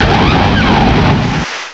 Add all new cries
cry_not_torterra.aif